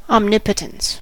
omnipotence: Wikimedia Commons US English Pronunciations
En-us-omnipotence.WAV